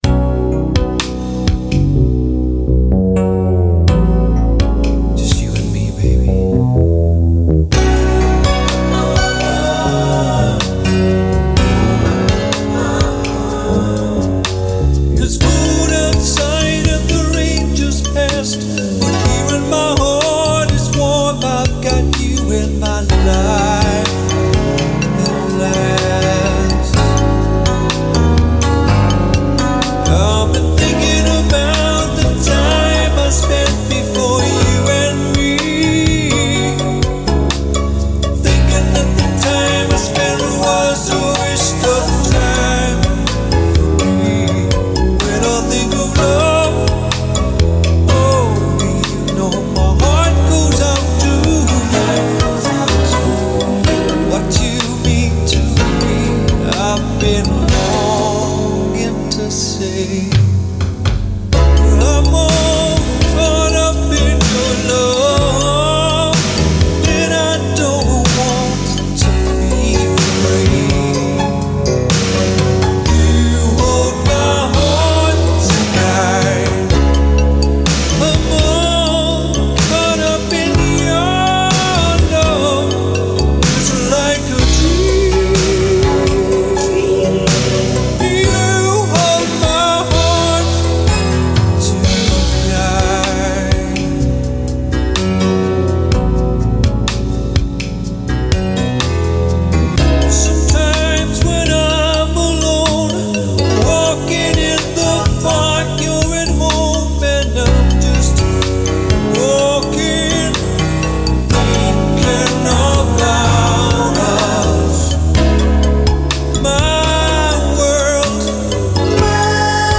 An old school romance song ...
keys
brass